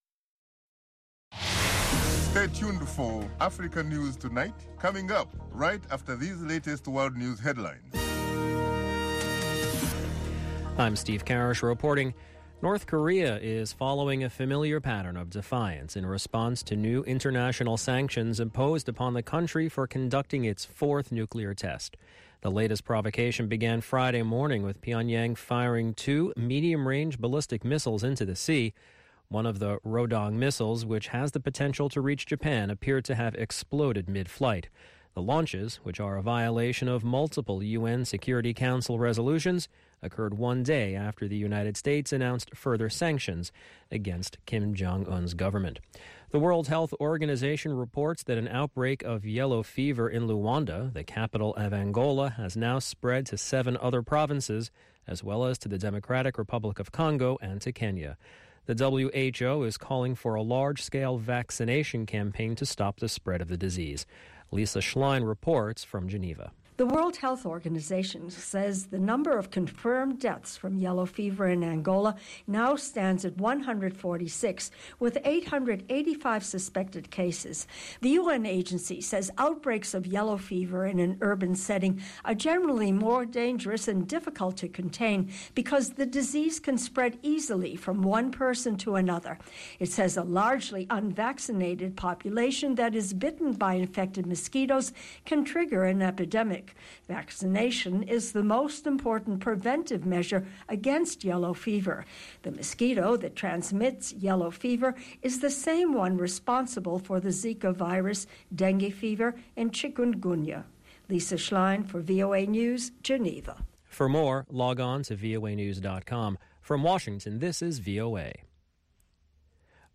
Africa News Tonight is a lively news magazine show featuring VOA correspondent reports, interviews with African officials, opposition leaders, NGOs and human rights activists.
Music and the popular sports segment, Sonny Side of Sports, round out the show.